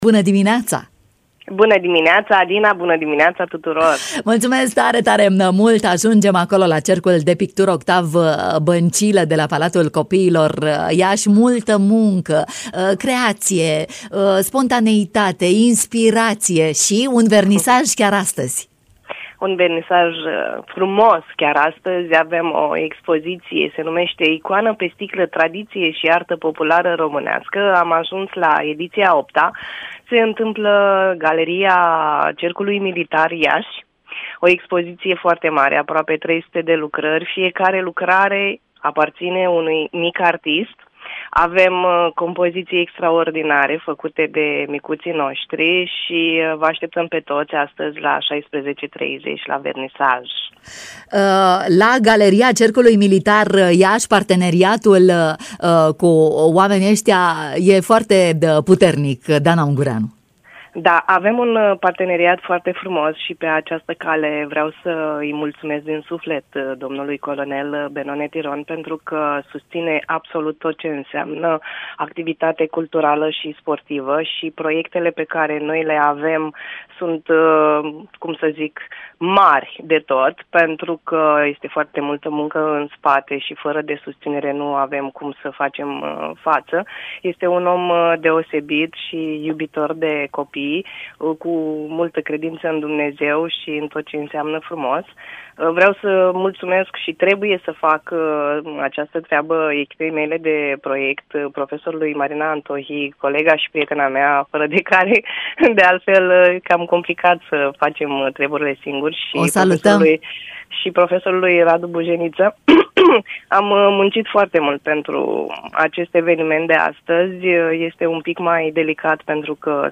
în matinal